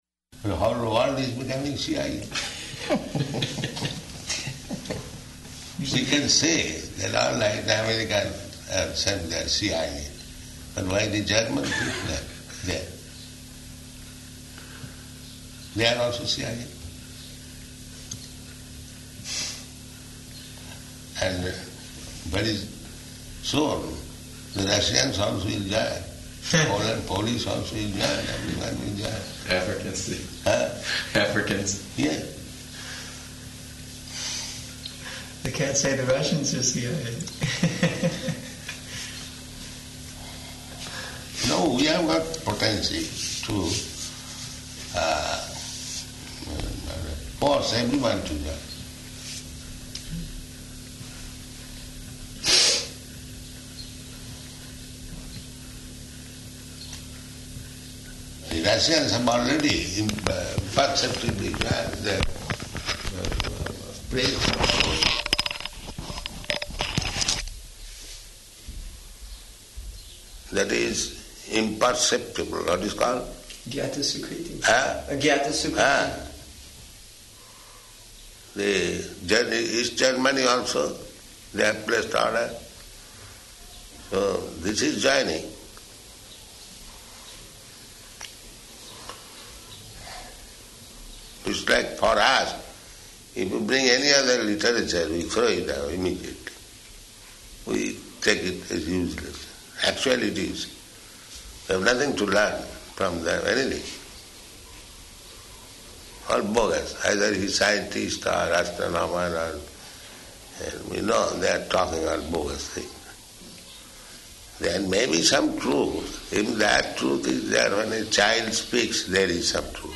Room Conversation
Room Conversation --:-- --:-- Type: Conversation Dated: August 3rd 1976 Location: New Māyāpur Audio file: 760803R2.NMR.mp3 Prabhupāda: The whole world is becoming CIA.